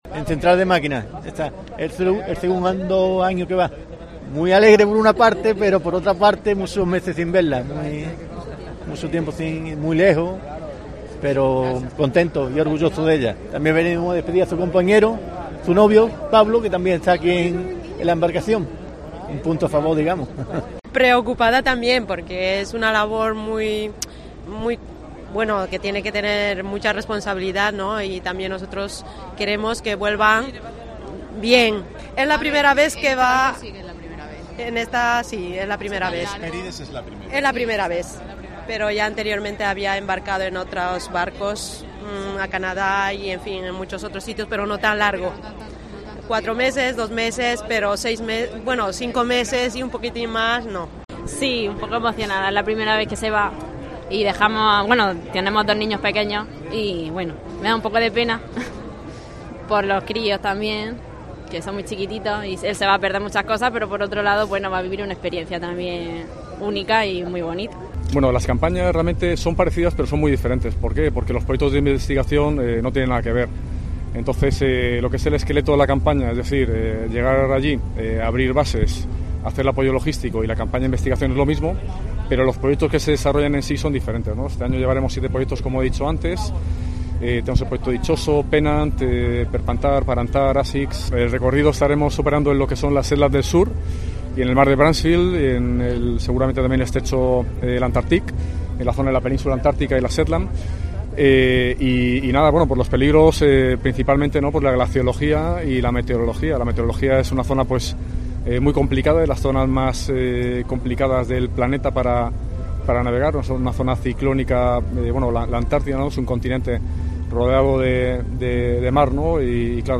Antes de emprender viaje, en el muelle del Arsenal, decenas de padres, hermanos, mujeres e hijos, muchos con lágrimas para depedir a los que han partido en esta nueva aventura y que han contado a Cope como afrontan una despedida tan larga.
El buque de investigación oceanográfica, ha sido despedido con honores militares y la música de la Unidad de Música del Tercio de Levante de Infantería de la Marina en un acto presidido por el Almirante de Acción Marítima, Vicealmirante Alfonso Delgado Moreno.